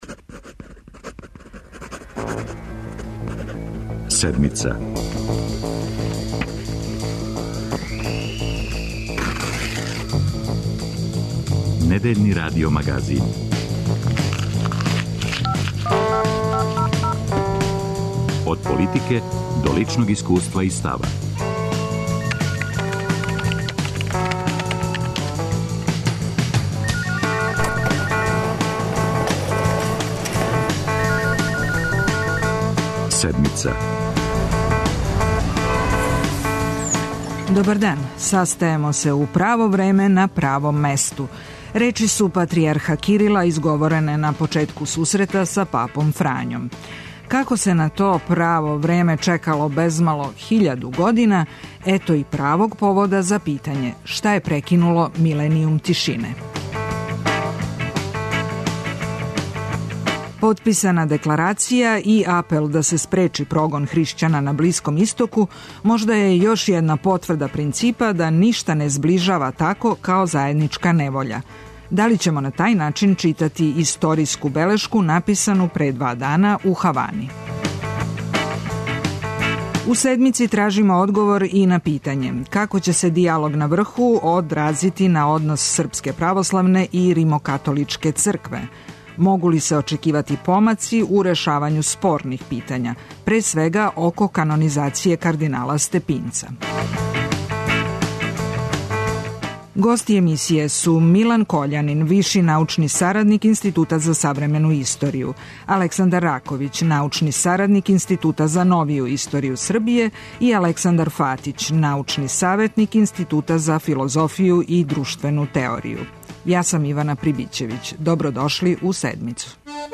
Путем телефона у разговор ће се укључити